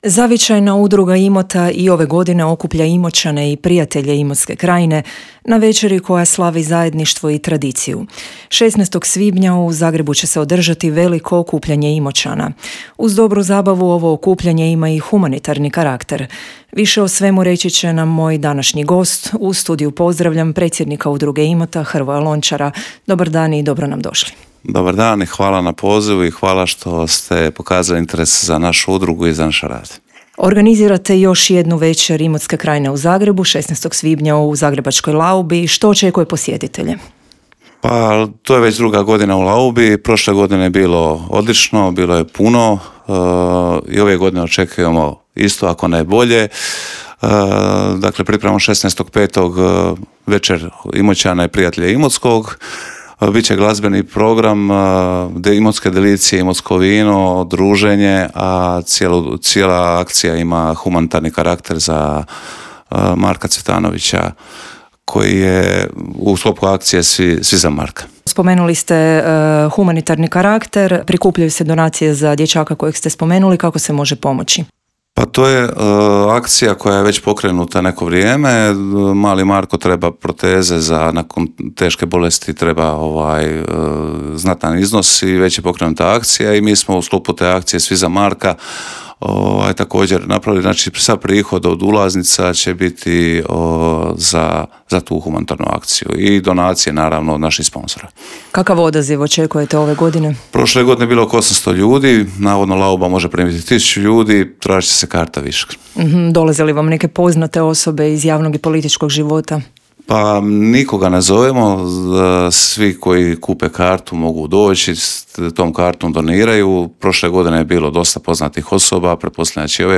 U subotu, 16. svibnja, u Zagrebu će se održati veliko okupljanje Imoćana. Uz dobru zabavu, okupljanje ima i humanitarni karakter. O svemu je u Intervjuu Media servisa